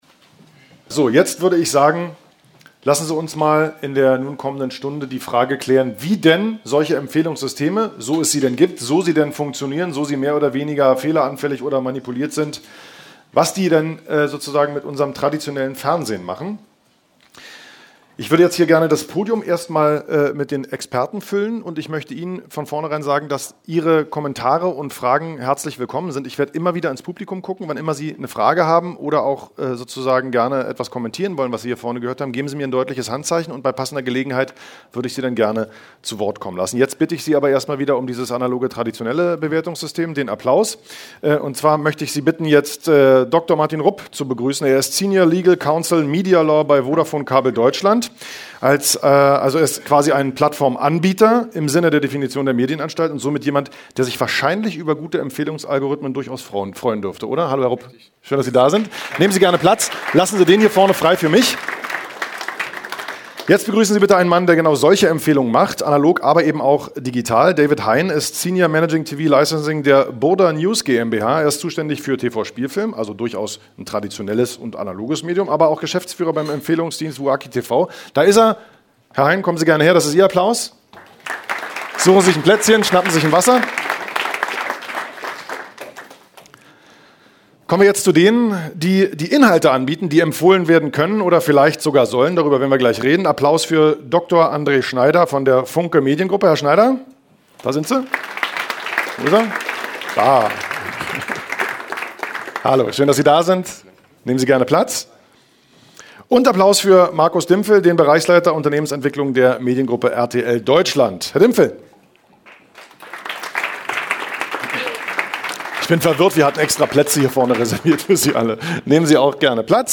Podiumsdiskussion